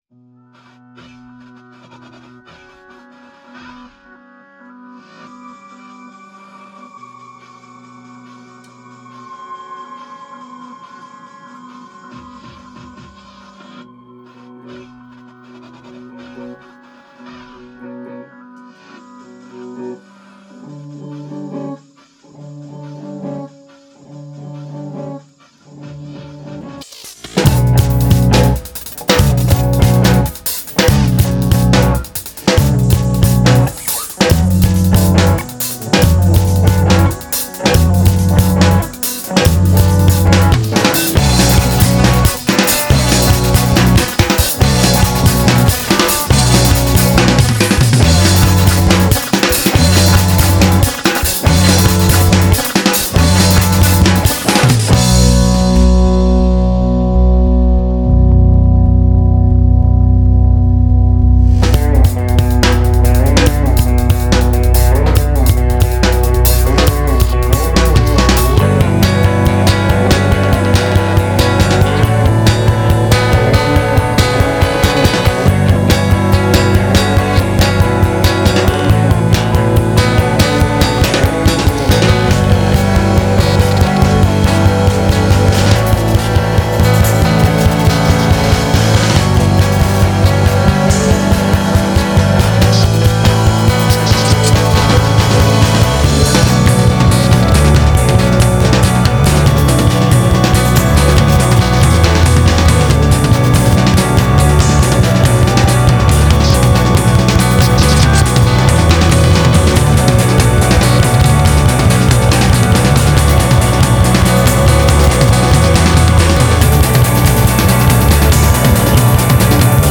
Genre:Post Rock / Math Rock